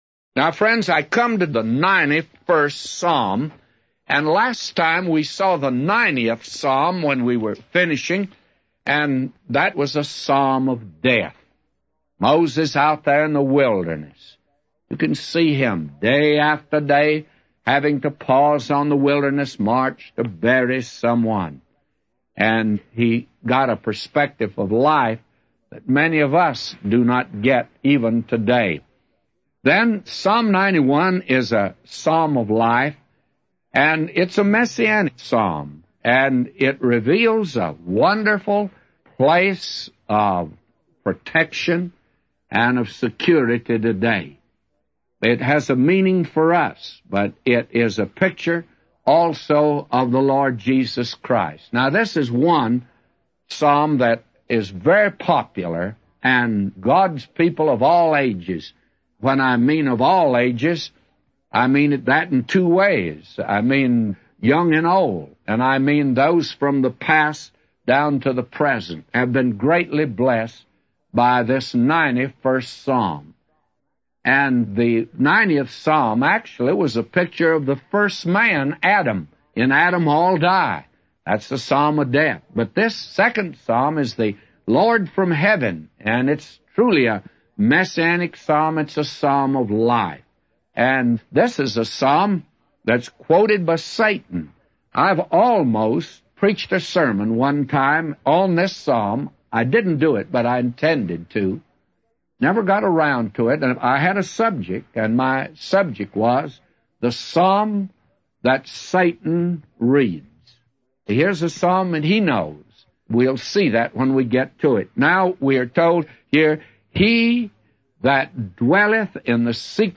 A Commentary By J Vernon MCgee For Psalms 91:1-999